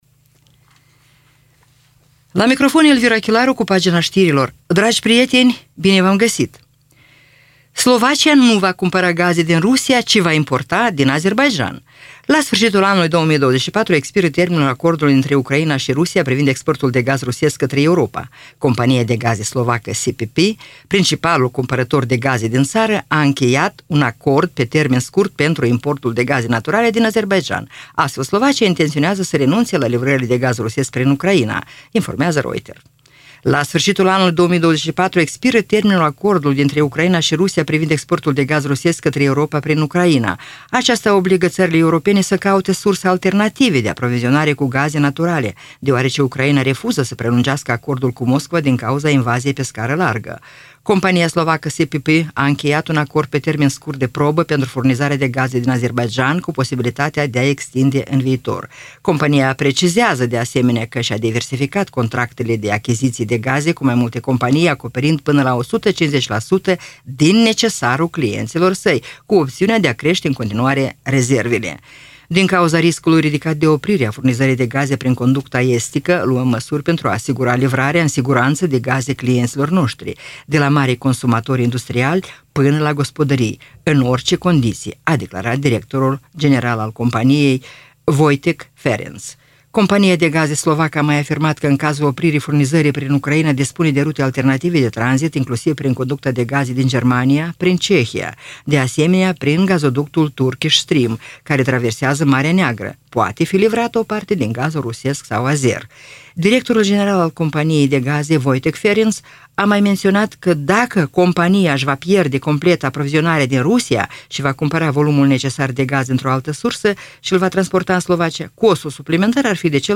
Știri Radio Ujgorod – 14.11.2024